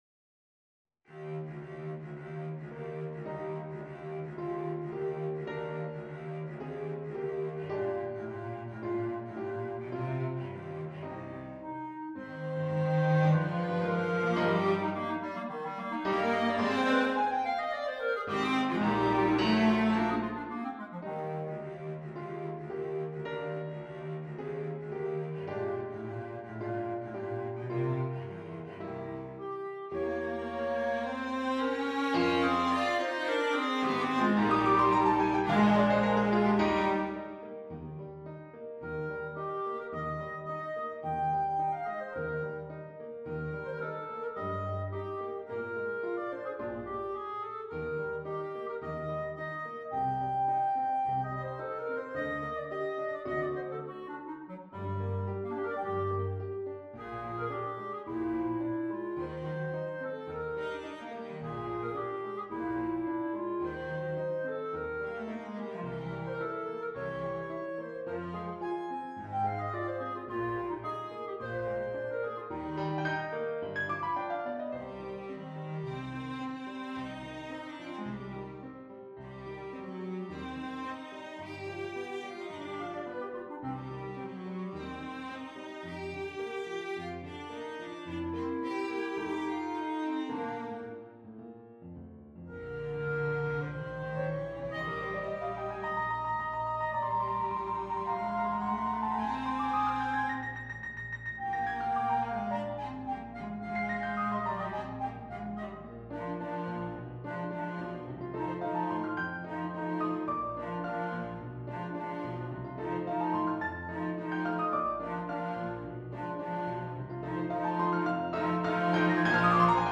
per clarinetto, violoncello  e pianofore